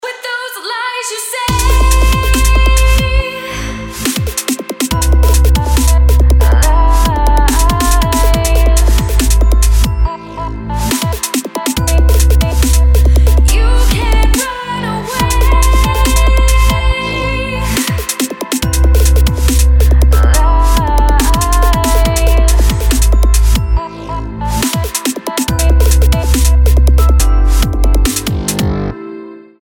• Качество: 192, Stereo
chillstep